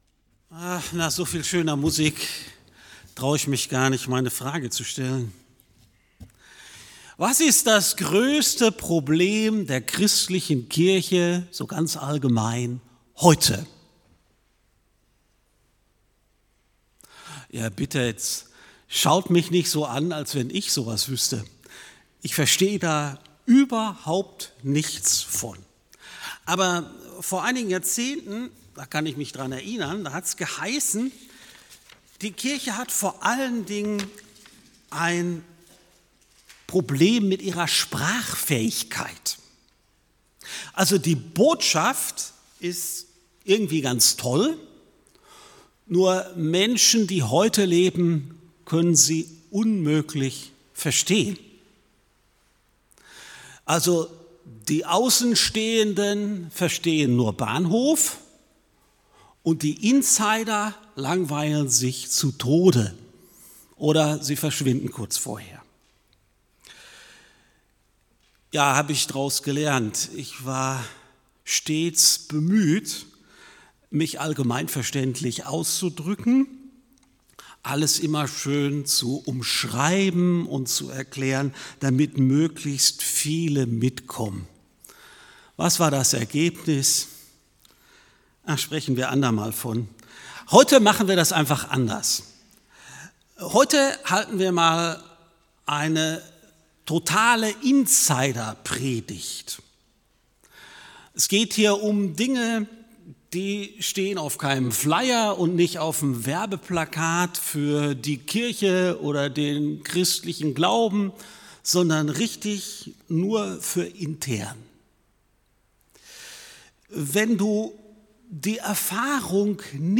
~ FeG Aschaffenburg - Predigt Podcast